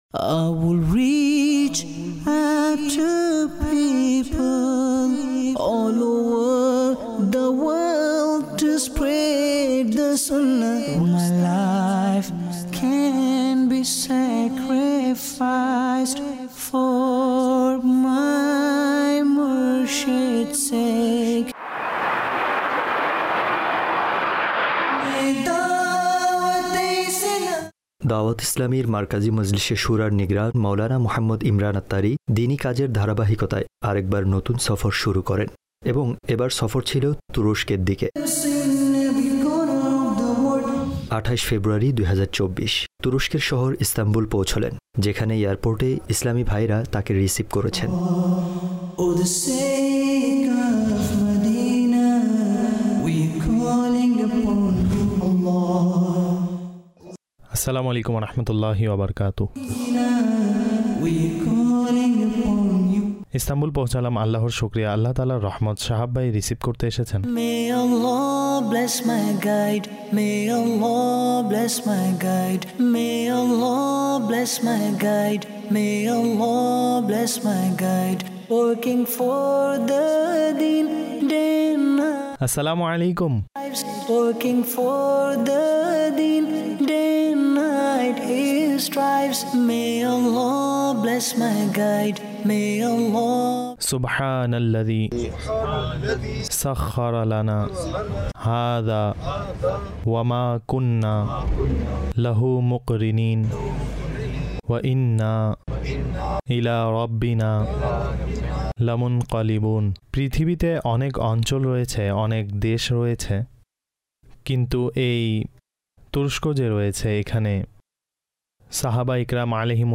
A journey to Some Countries (বাংলায় ডাবিংকৃত) EP# 45